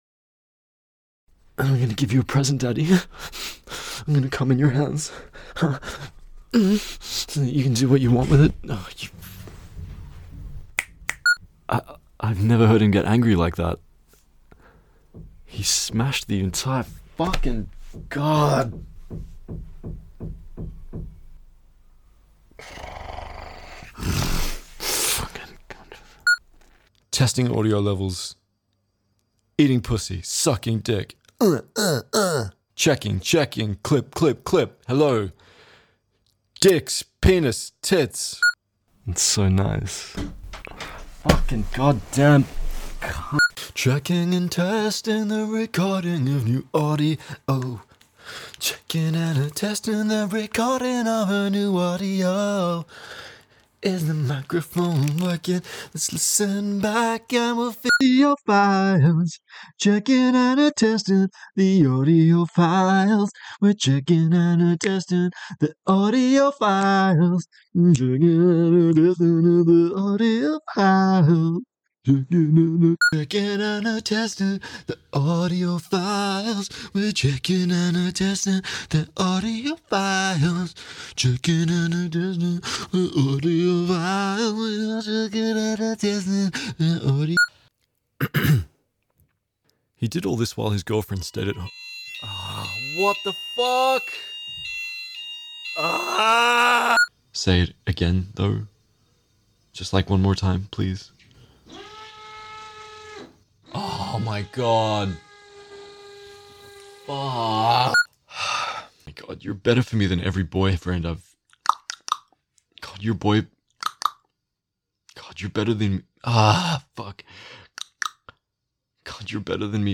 There aren't too many entertaining mistakes these days, so this 4-minute clip was two years in the making. For the uninitiated, the finger and tongue clicking after a mistake is used to create a spike in the audio waveform which allows me to easily, visually spot where the errors are.
You can hear him complimenting the paintings I had lying around.